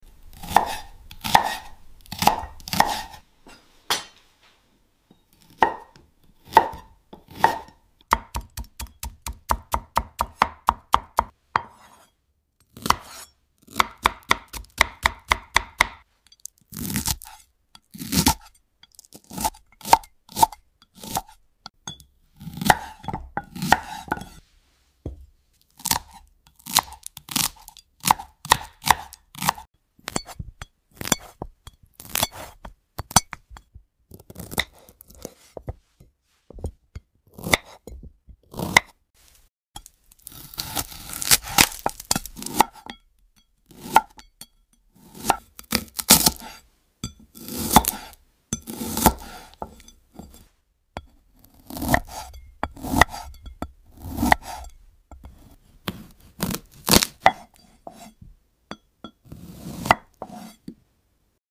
Glass cutting like you’ve never